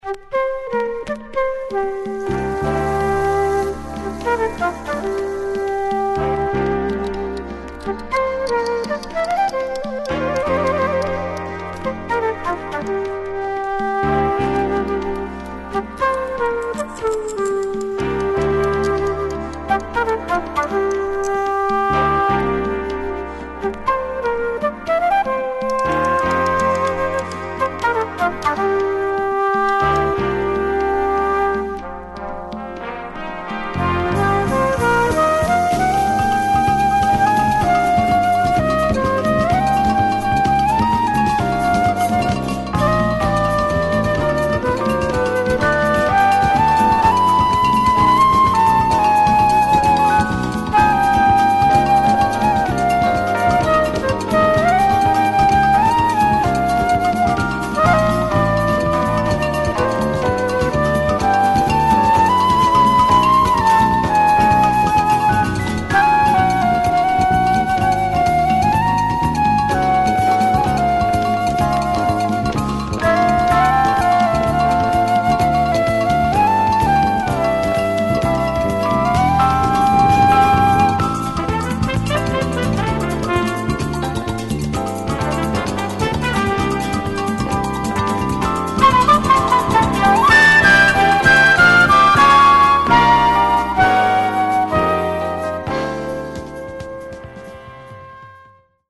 Mellow Latin Jazz